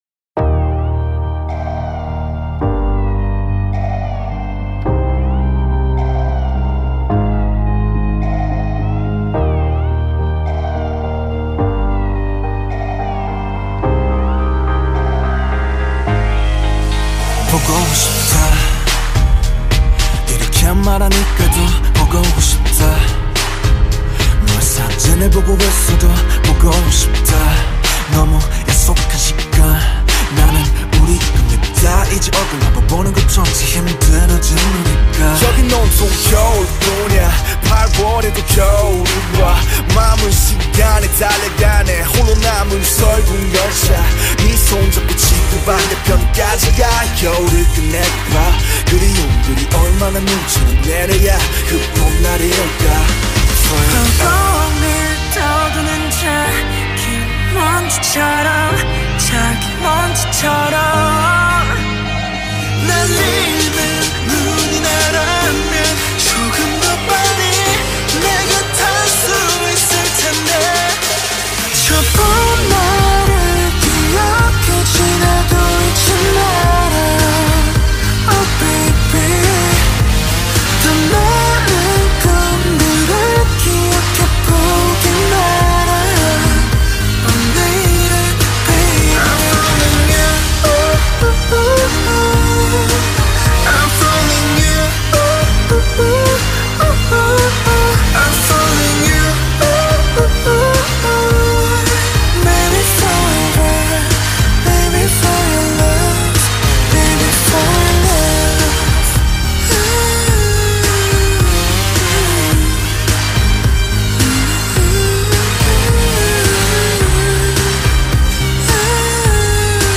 アラーム
ふくろうか鳩？の音